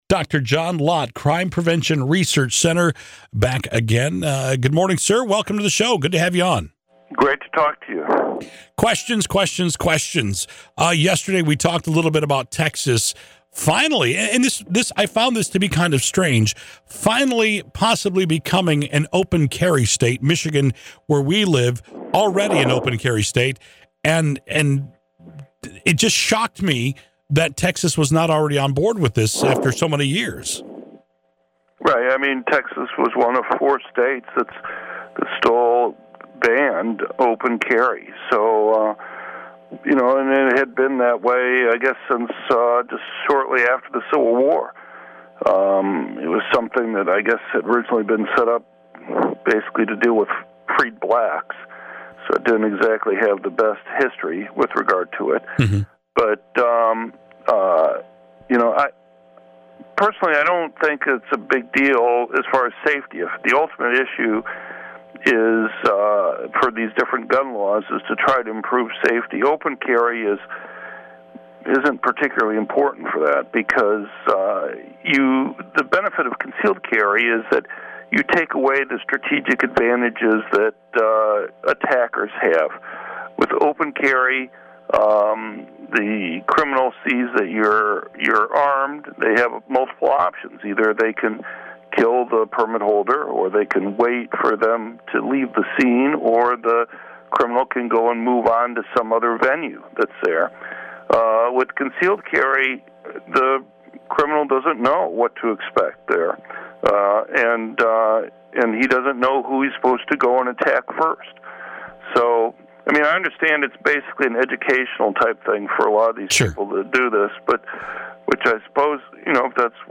John Lott was interviewed on the Michigan Talk Network to discussion Texas’ new Open Carry and Campus Carry Laws. While Lott doesn’t think that Open Carry will make a major improvement in safety, he was much more optimistic about the impact of the new campus carry law (the interview was on Tuesday, June 2, 2015 from 6:33 to 6:52 AM).